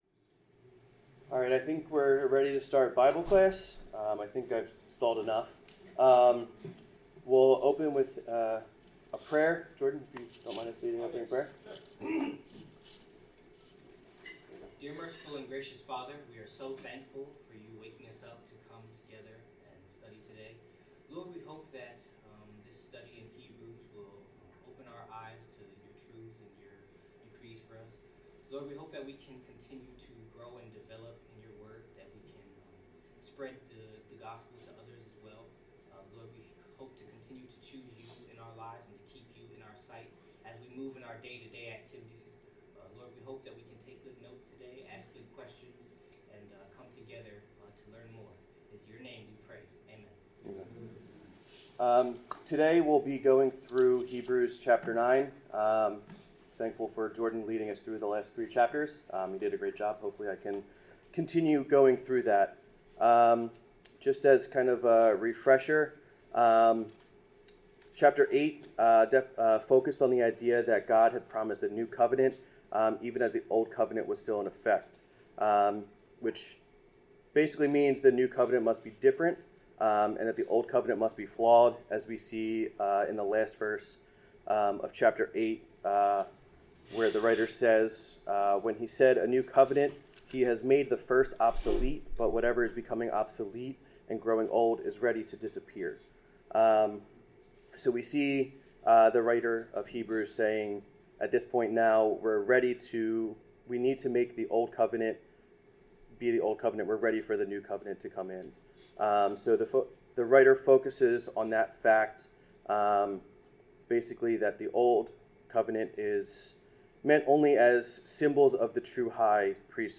Bible class: Hebrews 9
Service Type: Bible Class